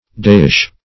dawish - definition of dawish - synonyms, pronunciation, spelling from Free Dictionary Search Result for " dawish" : The Collaborative International Dictionary of English v.0.48: Dawish \Daw"ish\, a. Like a daw.